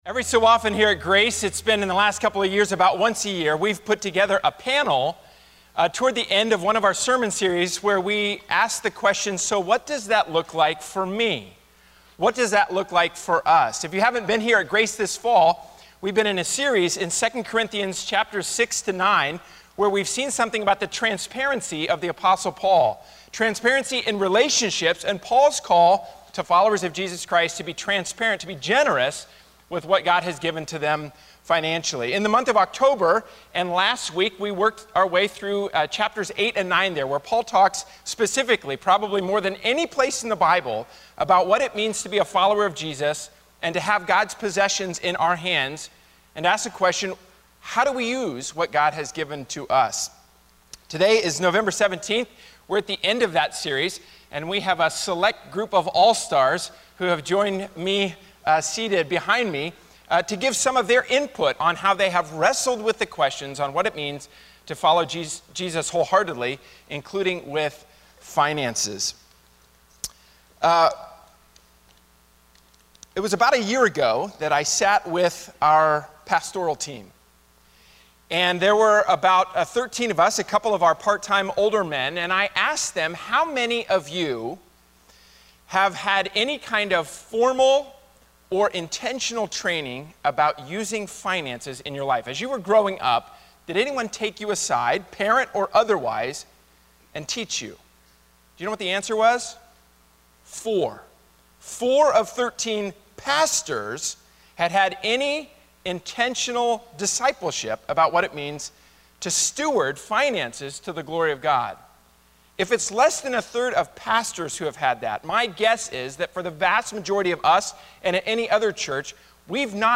Guest panelists discuss takeaways from our “Out of the Harbor” sermon series in Acts 17.
A discussion about biblical generosity and how to apply those principles in different walks of life.